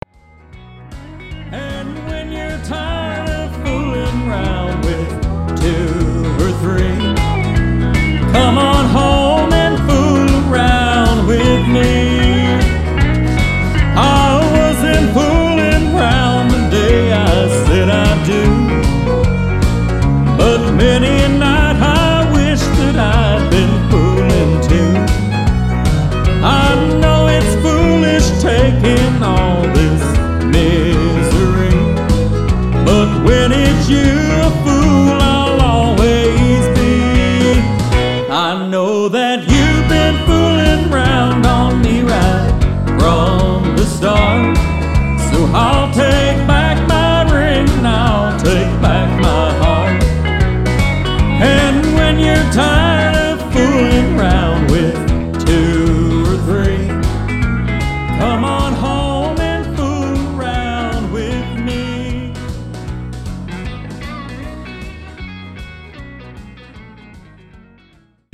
11 Country Songs